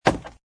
woodwood.mp3